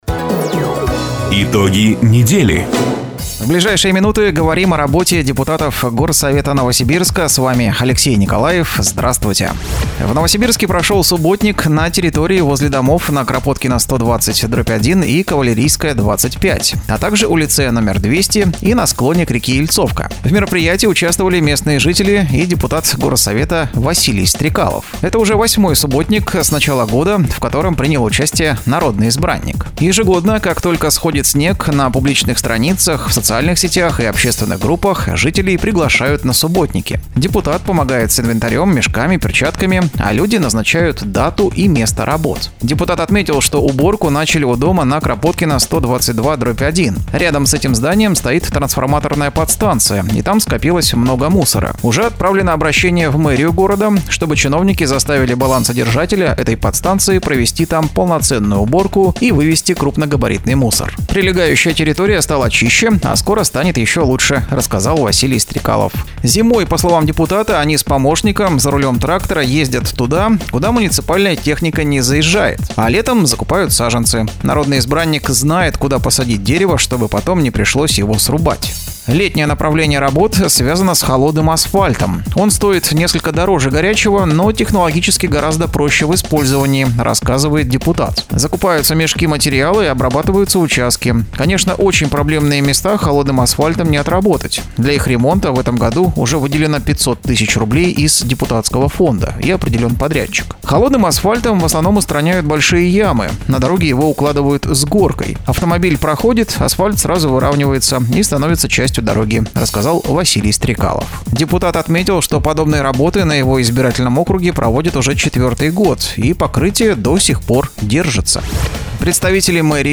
Запись программы "Итоги недели", транслированной радио "Дача" 20 мая 2023 года.